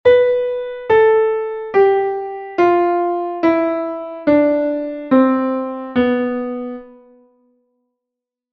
Mixolidio
si-la-sol-fa-mi-re-do-si